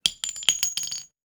weapon_ammo_drop_13.wav